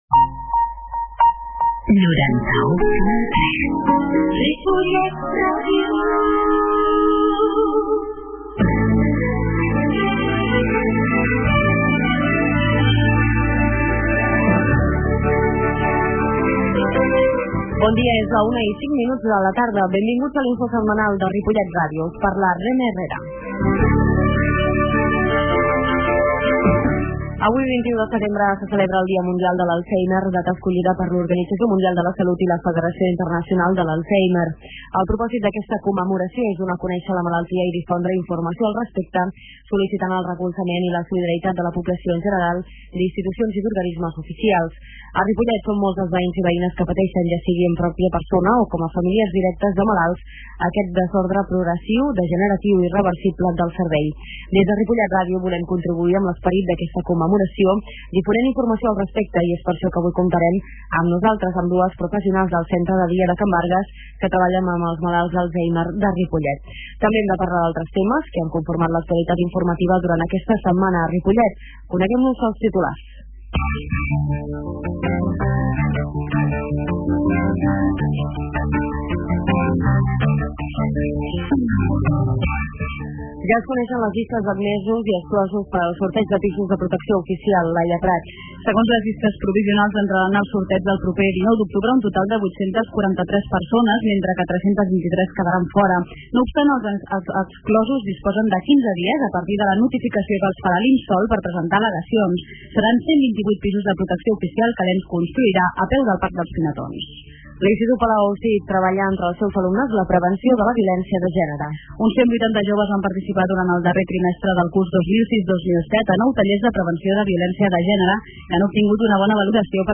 Comunicació Info de la setmana: 21 de setembre -Comunicació- 21/09/2007 Escolteu en directe per la r�dio o la xarxa el resum de not�cies de Ripollet R�dio, que s'emet els divendres a les 13 hores.